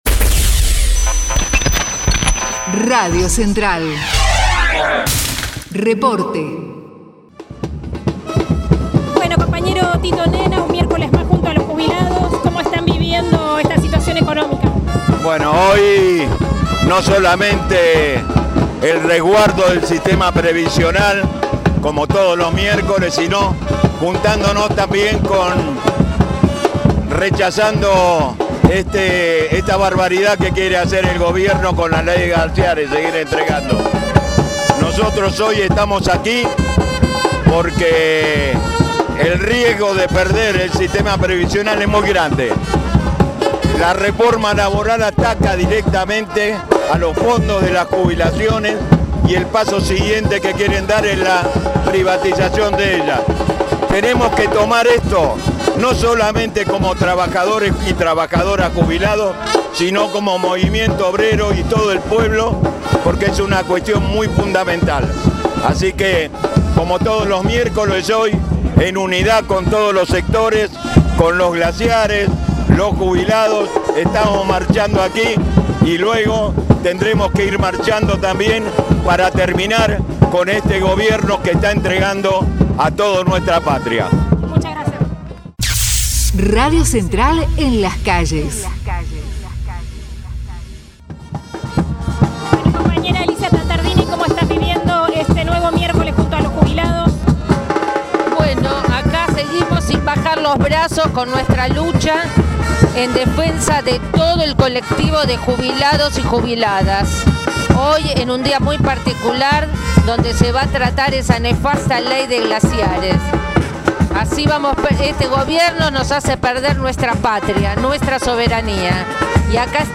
MARCHA DE JUBILADOS AL CONGRESO: Testimonios CTA
2026_marcha_jubilados_8_abril.mp3